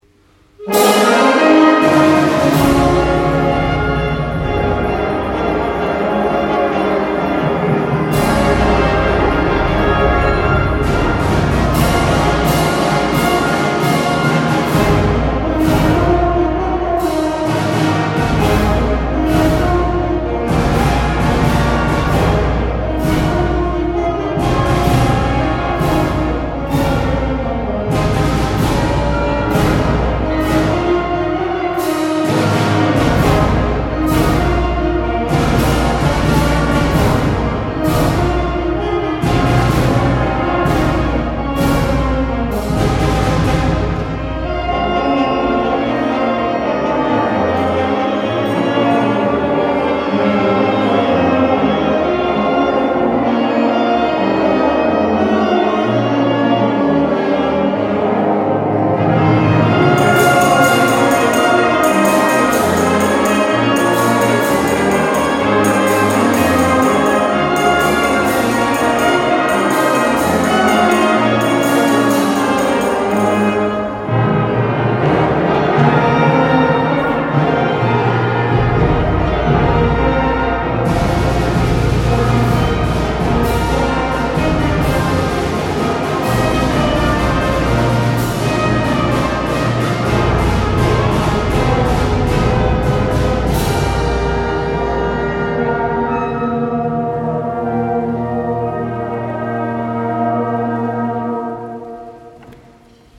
昼休みに音楽集会がありました。
すばらしい歌声と演奏に聞いていた子ども達は感動していました。
ブラバン１.mp3